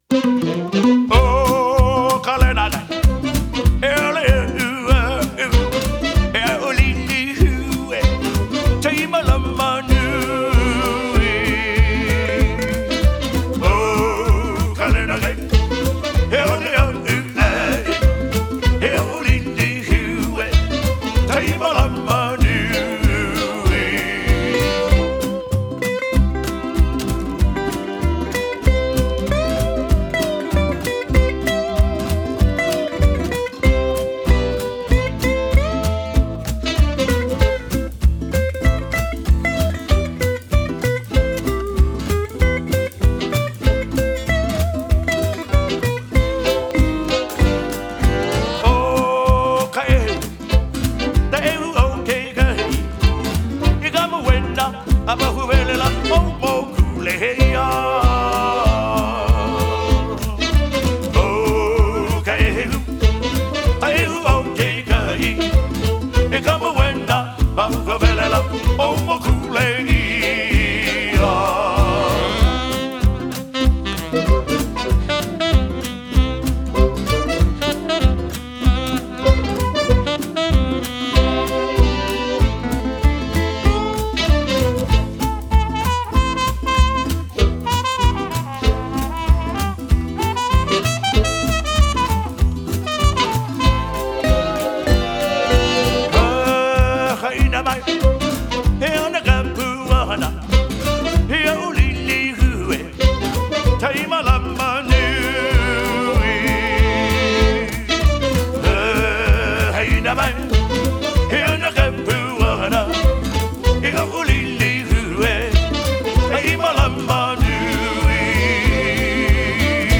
filled the night with lively 1930s-style Hawaiian swing.